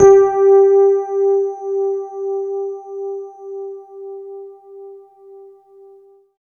47 RHOD G4-L.wav